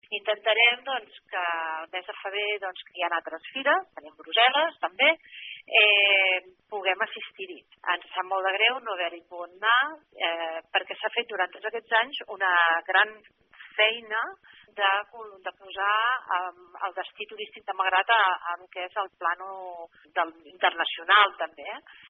El fet que l’empresa Malgrat Turisme estigui en el procés de dissolució i que encara no es tingui el pressupost definitivament aprovat, obliga al consistori a no ser a Fitur en aquesta edició. Ho diu la regidora de trisme, Esther Martínez.